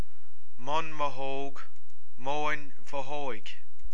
Pronunciation Audio File